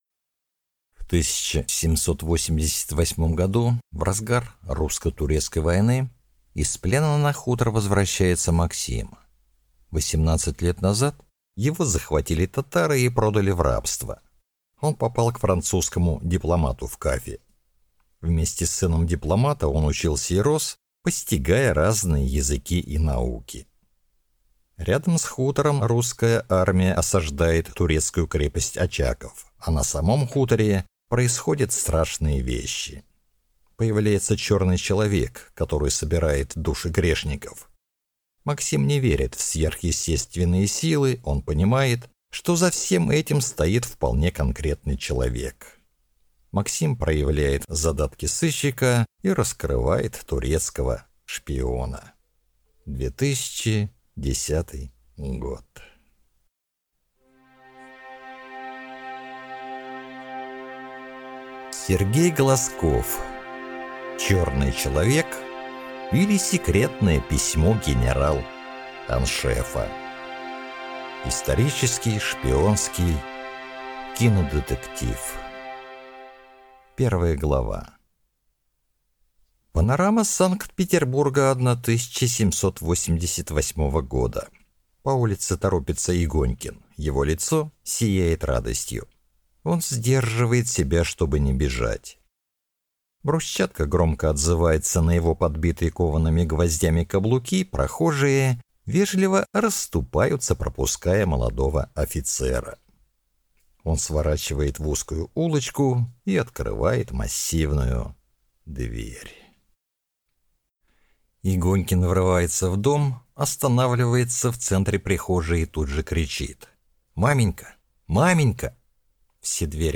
Аудиокнига Черный человек, или Секретное письмо генерал-аншефа | Библиотека аудиокниг